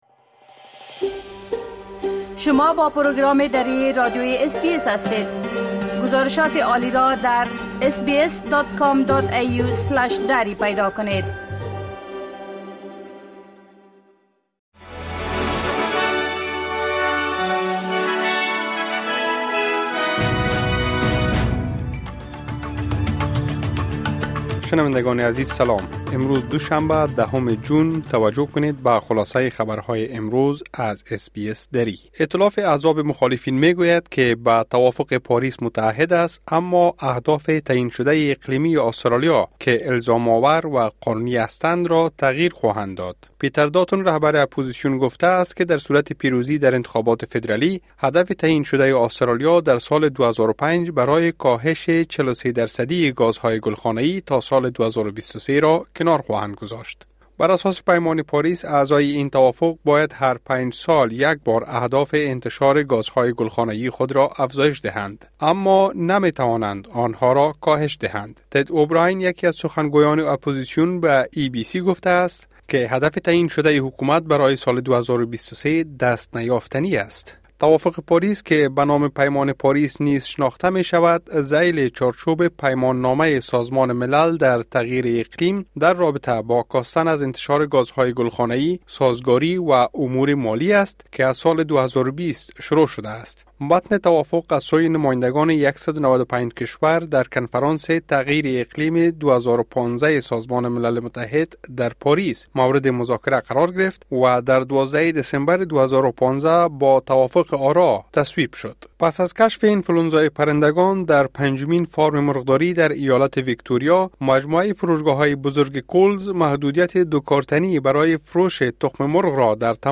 خلاصۀ مهمترين خبرهای روز از بخش درى راديوى اس بى اس|۱۰ جون ۲۰۲۴